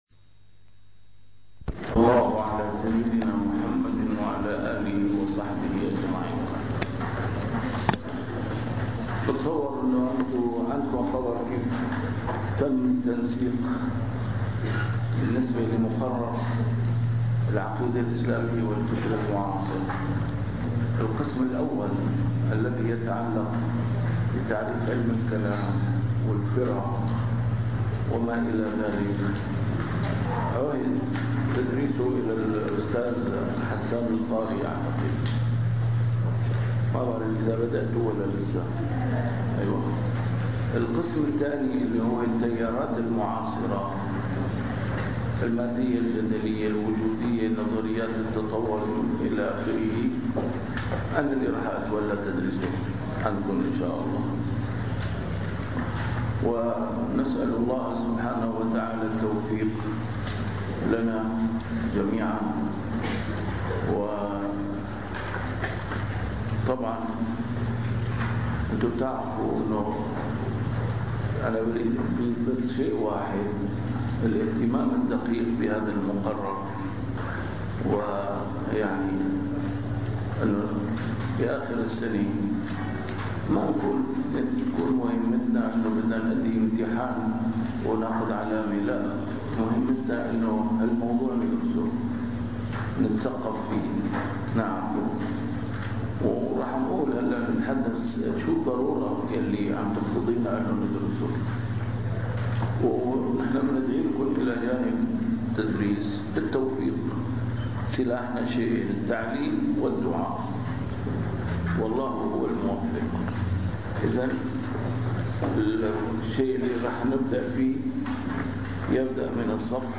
المحاضرة الأولى: المادية الجدلية ونقدها